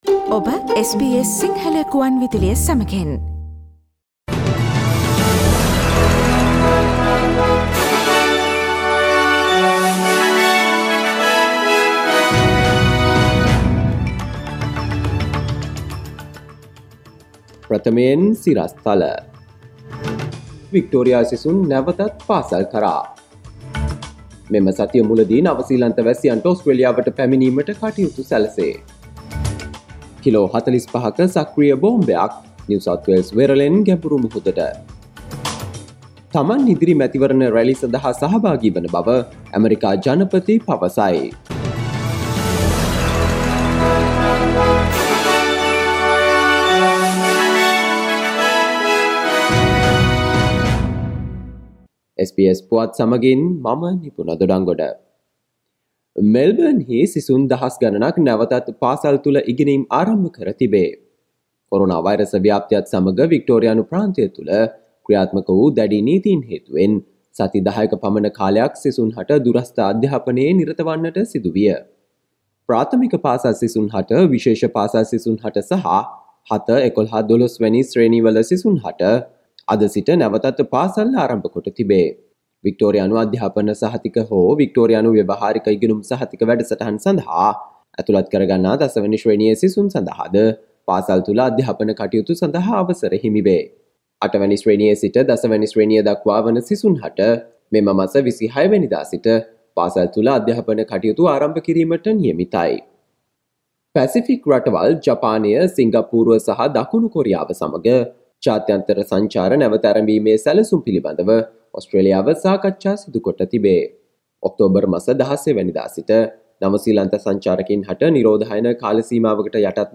Daily News bulletin of SBS Sinhala Service: Monday 12 October 2020
Today’s news bulletin of SBS Sinhala Radio – Monday 12 October 2020 Listen to SBS Sinhala Radio on Monday, Tuesday, Thursday and Friday between 11 am to 12 noon